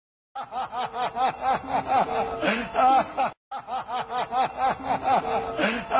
risada-hahahha.mp3